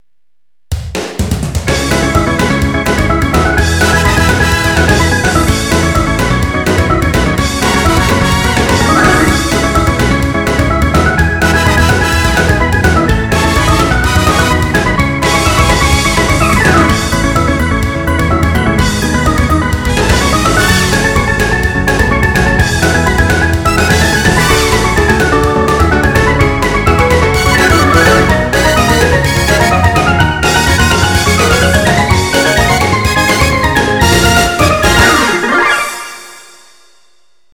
ぷろぐれっしヴ！　オルガンで激しいコードがどんどんあがってく曲作ってみたかったの。
遅くしてみた。